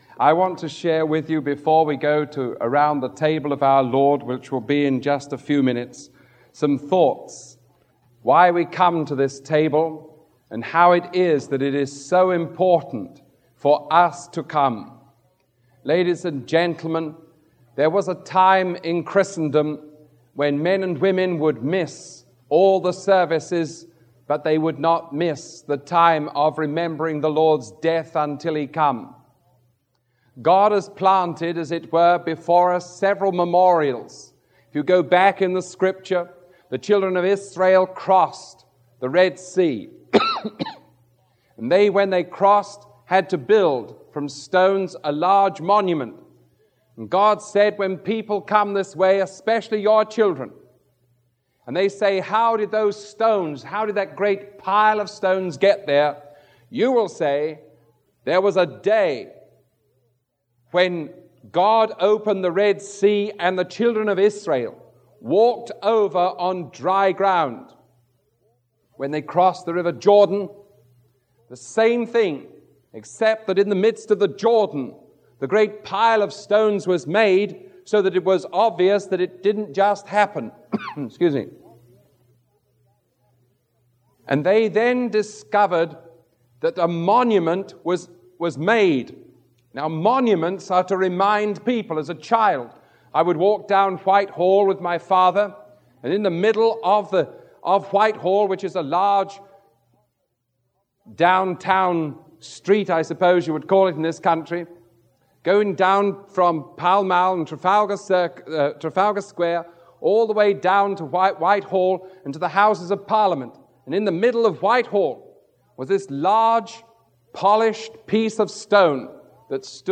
Communion , The Lord's Supper Romans